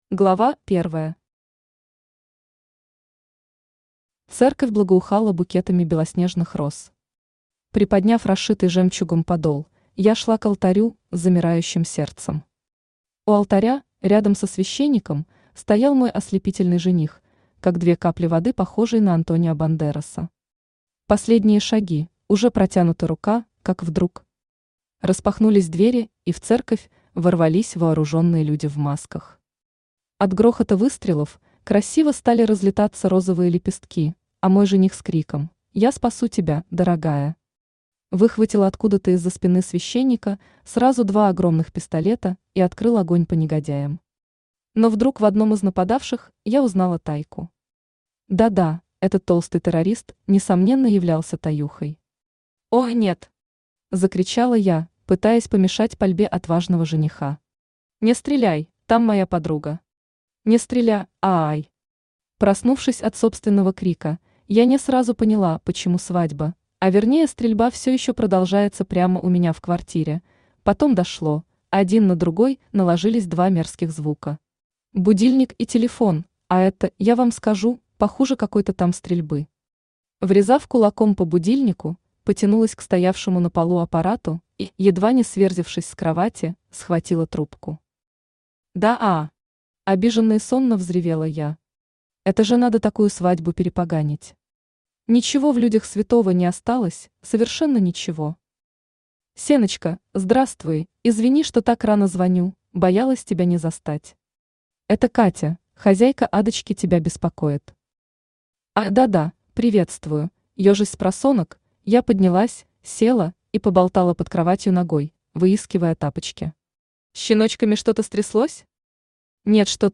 Аудиокнига Курортная фишка